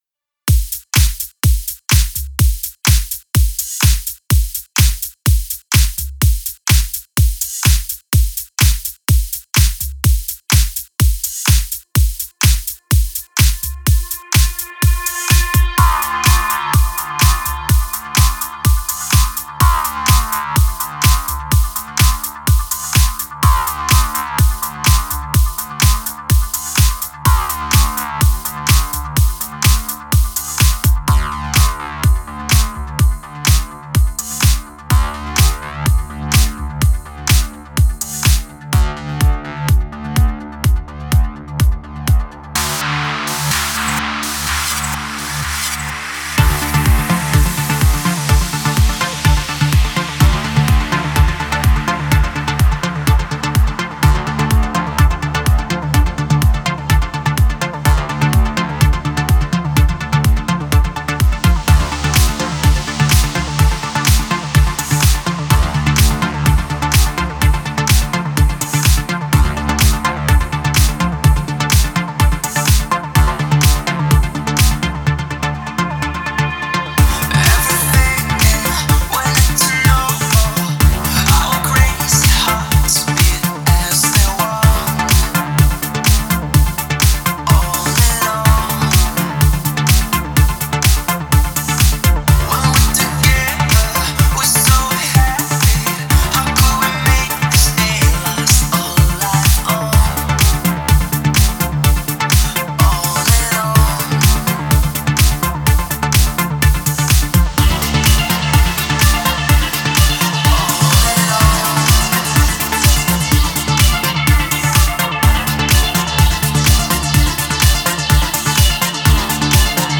это энергичный трек в жанре прогрессивного house